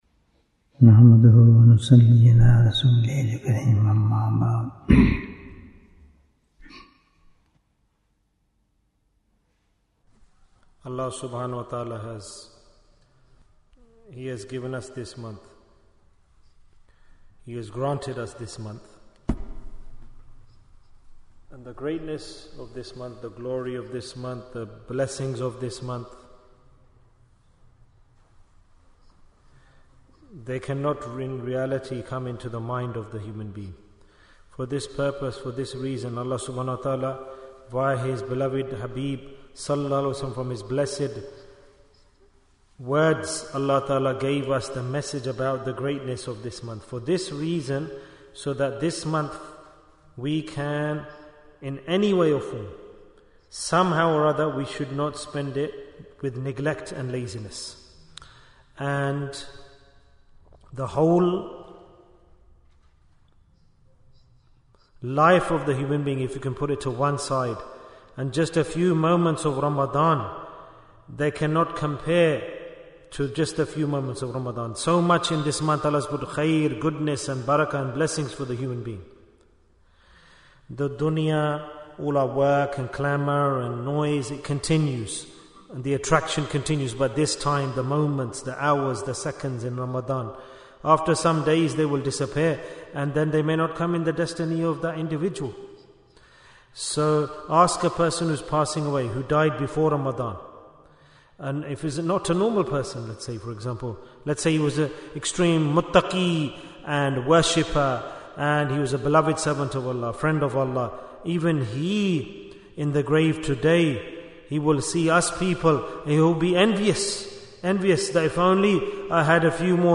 Jewels of Ramadhan 2025 - Episode 7 - What Do You Gain by Staying Awake at Night? Bayan, 29 minutes5th March, 2025